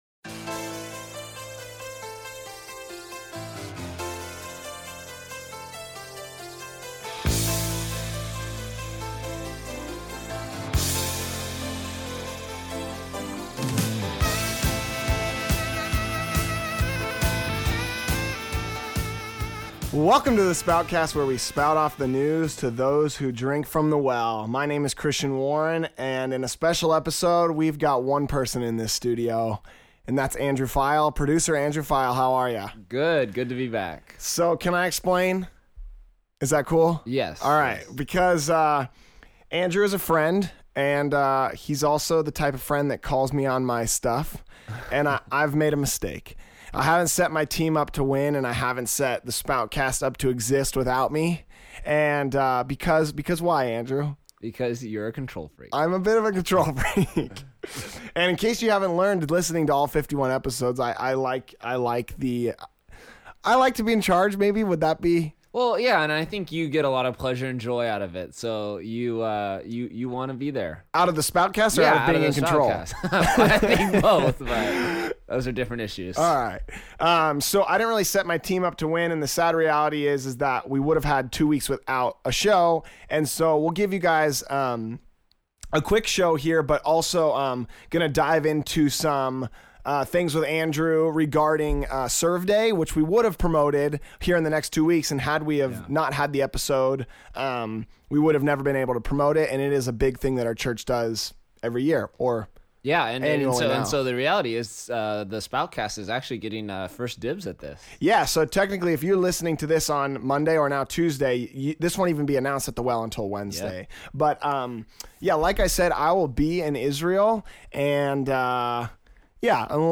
There is no music this week.